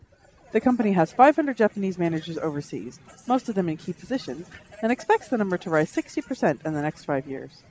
BUS
noisy